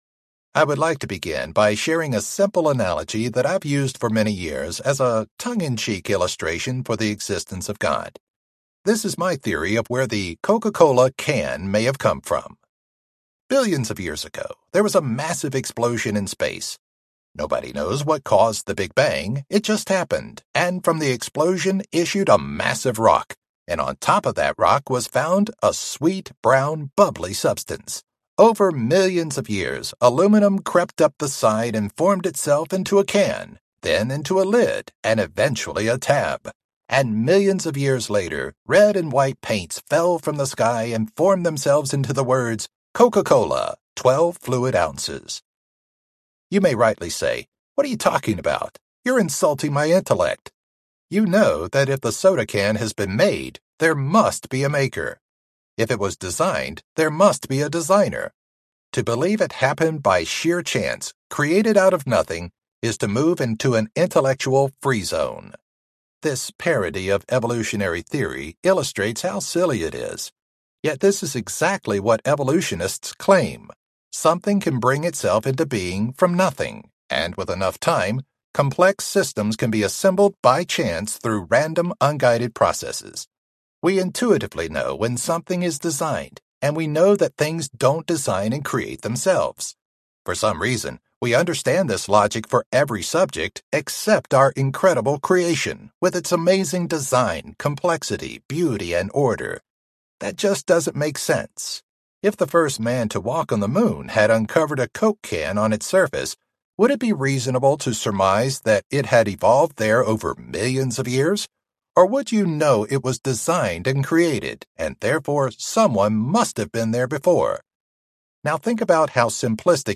How to Know God Exists Audiobook
4.9 Hrs. – Unabridged